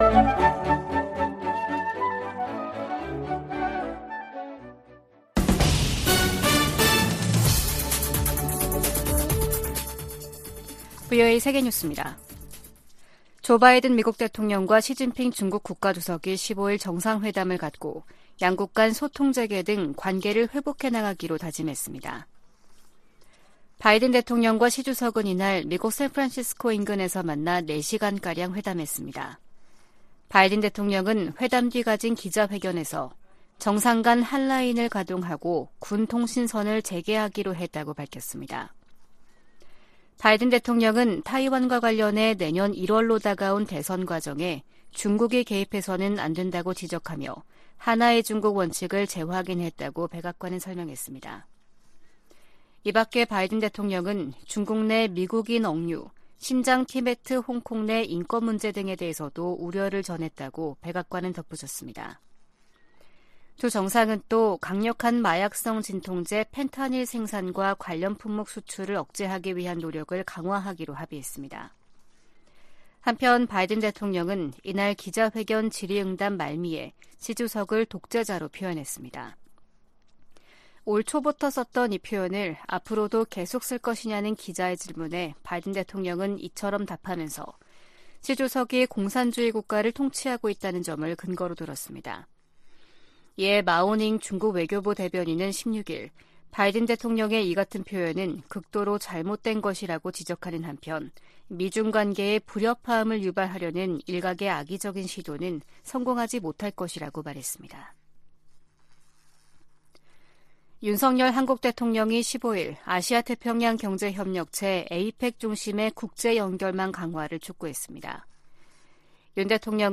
VOA 한국어 아침 뉴스 프로그램 '워싱턴 뉴스 광장' 2023년 11월 17일 방송입니다. 조 바이든 미국 대통령이 15일 시진핑 중국 국가 주석과의 회담에서 한반도의 완전한 비핵화에 대한 미국의 의지를 다시 한번 강조했습니다. 유엔총회 제3위원회가 북한의 인권 유린을 규탄하는 북한인권결의안을 19년 연속 채택했습니다. 북한과 러시아는 정상회담 후속 조치로 경제공동위원회를 열고 무역과 과학기술 등 협력 방안에 합의했습니다.